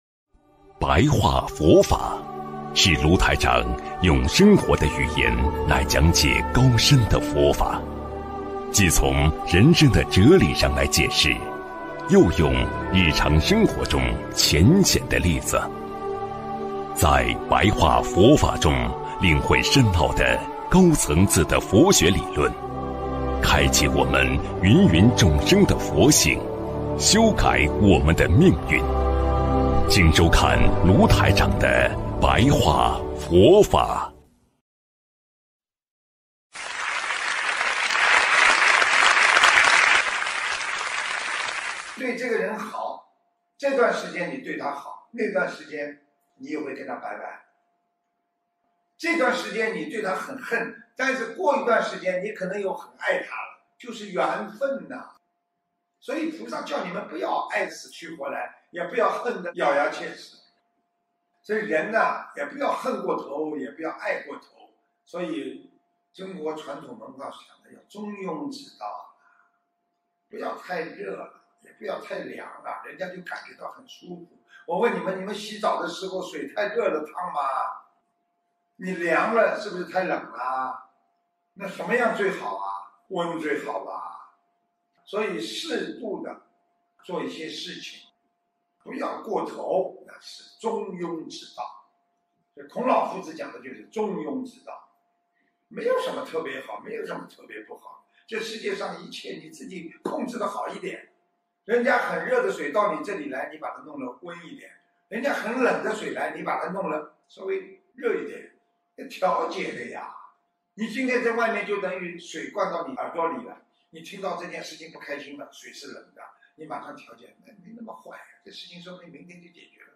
首页 >>佛法书籍 >> 广播讲座